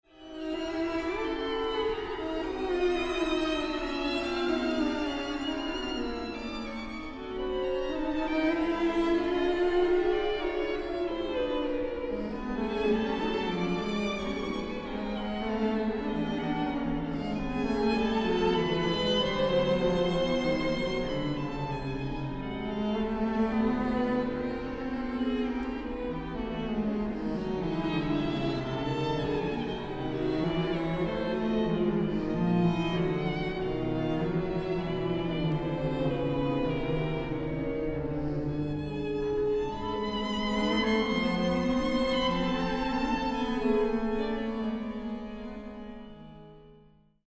Quintette avec 2 violoncelles - Studio Robert VERGUET
quintette-avec-2-cello-1er-mvt-E.mp3